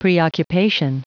Prononciation du mot preoccupation en anglais (fichier audio)
Prononciation du mot : preoccupation